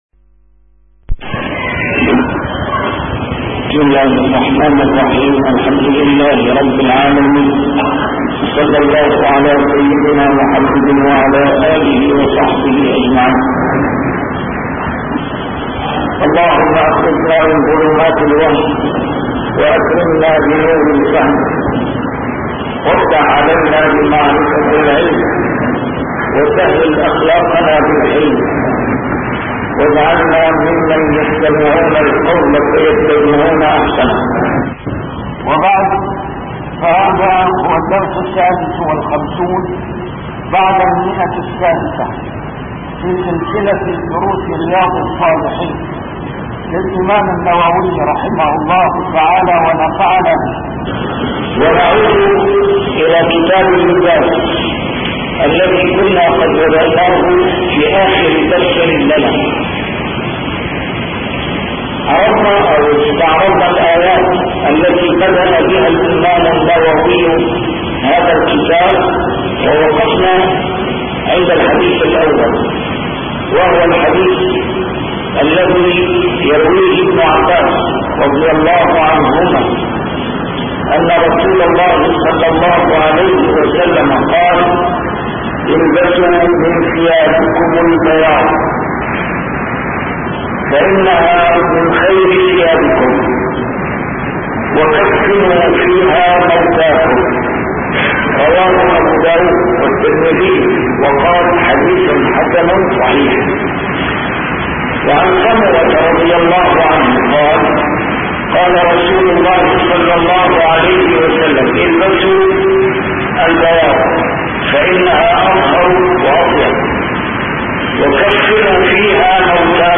A MARTYR SCHOLAR: IMAM MUHAMMAD SAEED RAMADAN AL-BOUTI - الدروس العلمية - شرح كتاب رياض الصالحين - 656- شرح رياض الصالحين: استحباب الثوب الأبيض